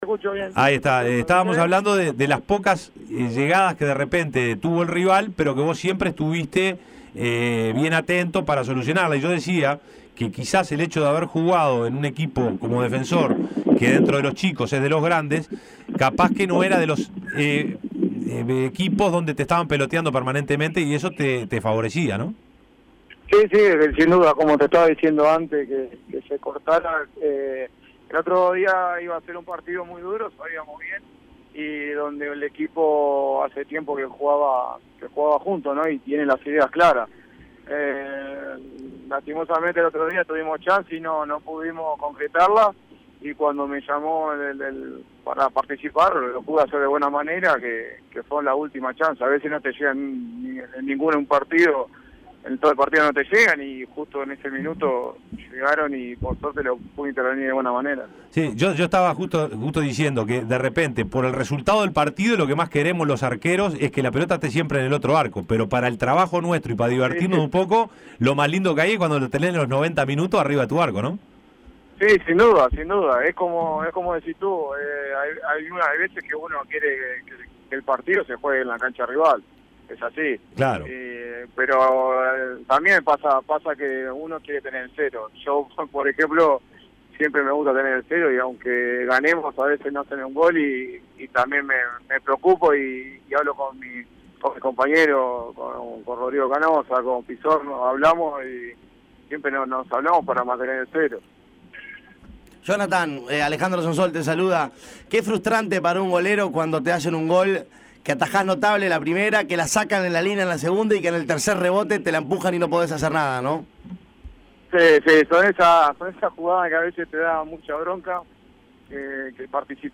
El "mono" habló con el Panel de Tuya y Mía y analizó el presente de su cuadro. Entrevista completa.